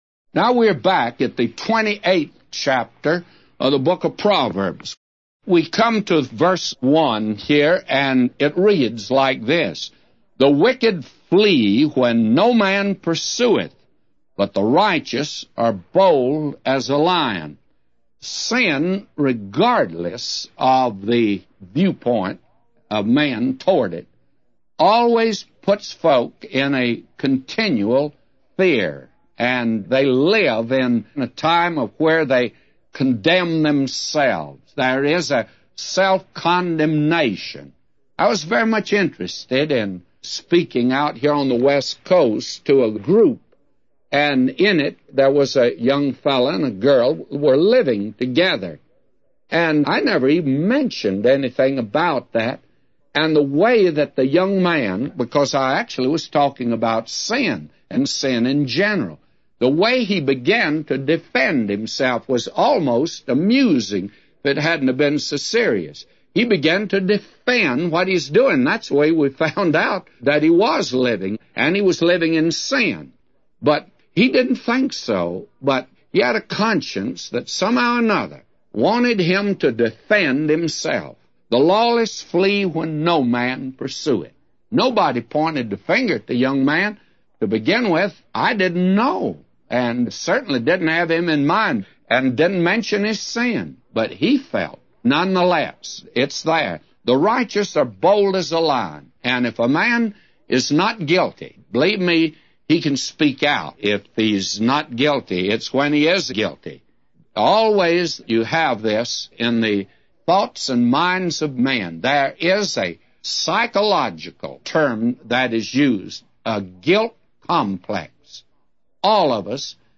A Commentary By J Vernon MCgee For Proverbs 28:1-999